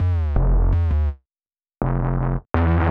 Free Bass